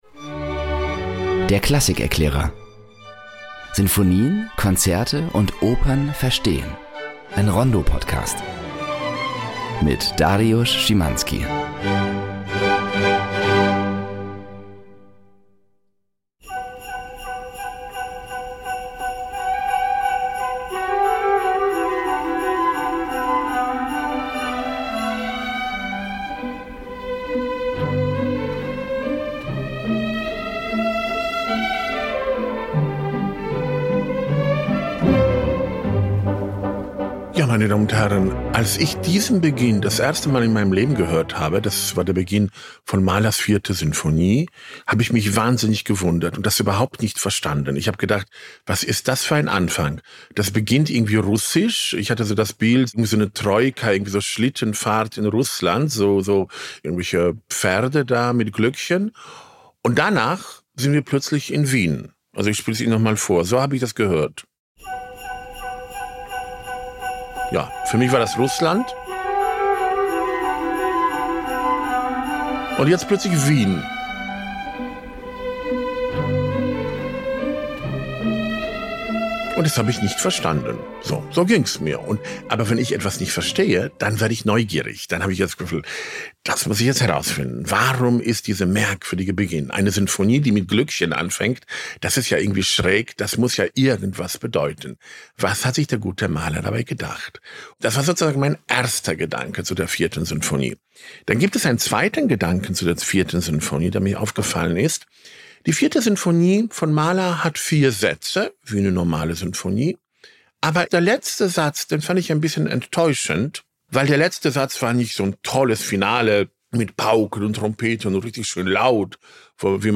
Um diesem Anfang auf die Spur zu kommen, hören wir die Sinfonie von hinten nach vorne und darüber hinaus: Es erklingen auch weitere Werke des Komponisten. So entwickelt sich die Folge zu einer ersten allgemeinen Mahler-Einführung.